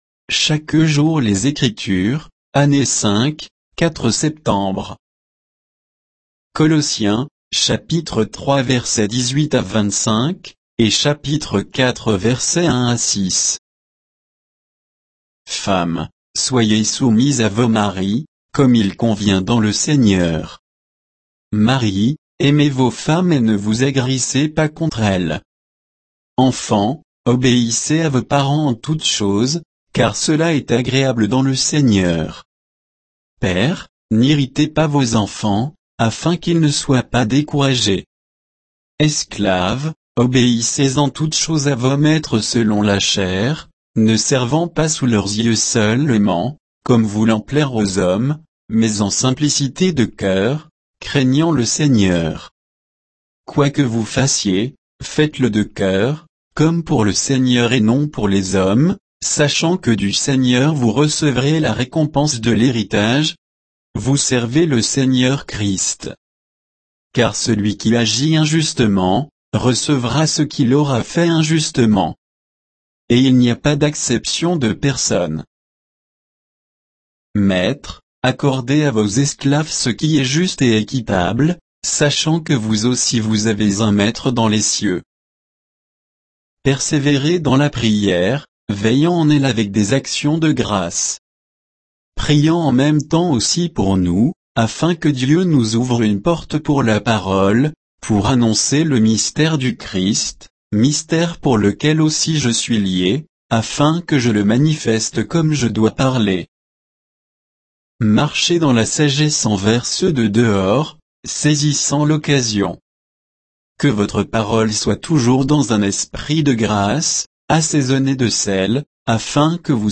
Méditation quoditienne de Chaque jour les Écritures sur Colossiens 3, 18 à 4, 6